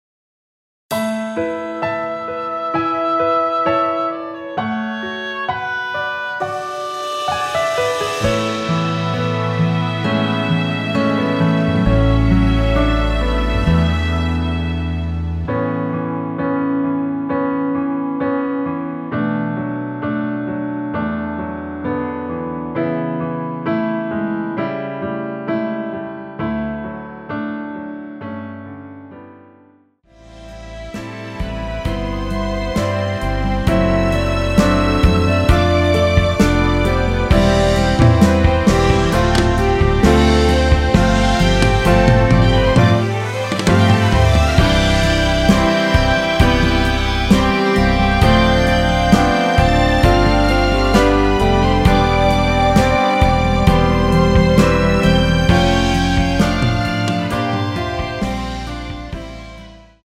원키에서(-1)내린 1절 + 후렴으로 편곡한 멜로디 포함된 MR 입니다.(미리듣기및 가사 참조)
앞부분30초, 뒷부분30초씩 편집해서 올려 드리고 있습니다.
중간에 음이 끈어지고 다시 나오는 이유는